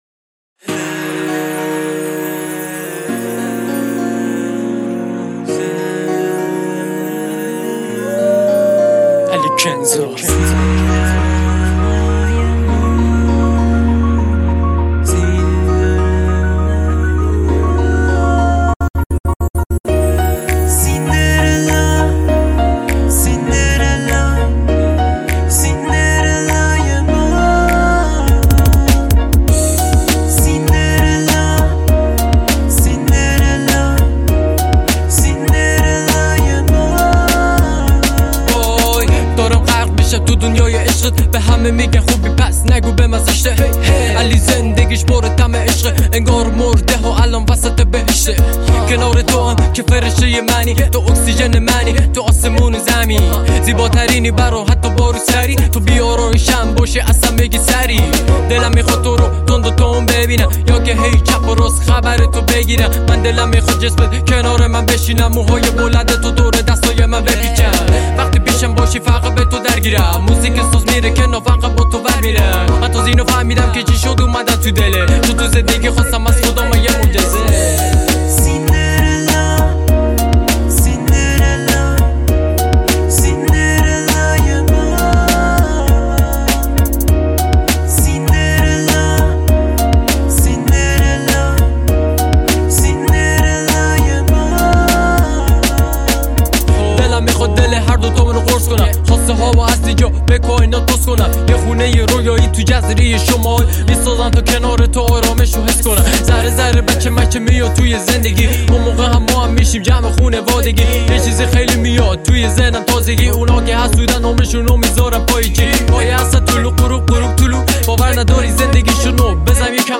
آهنگ عاشقانه
اهنگ عاشقانه رپ
آهنگهای رپ عاشقانه